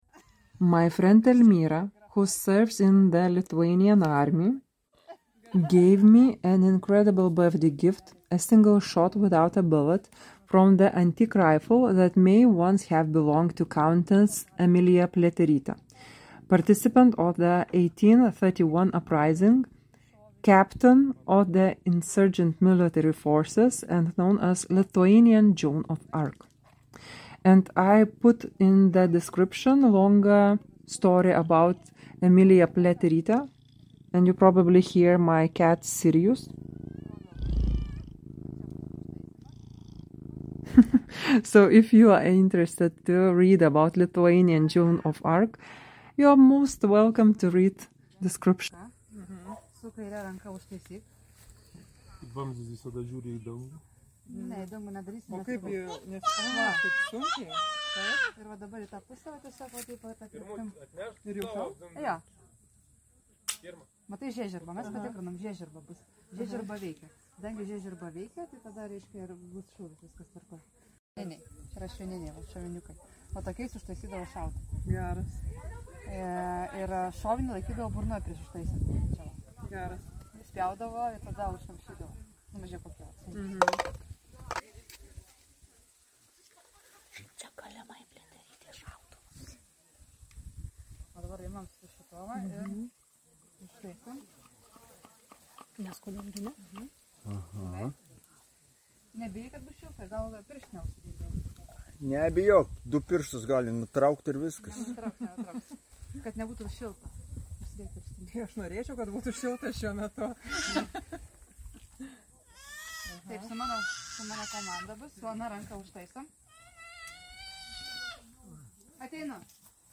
a single shot (without a bullet) from an antique rifle